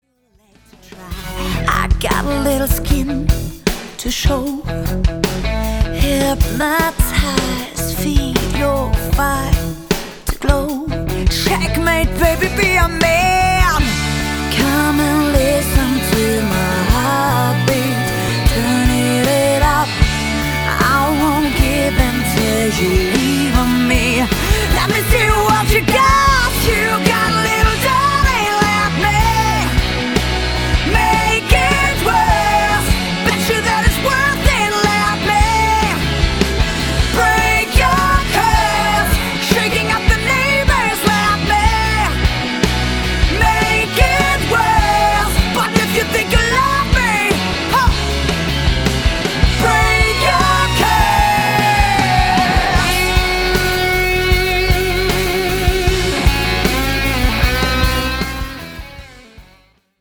vocals
guitars, acoustic guitar
keyboards, piano
bass, background vocals
drums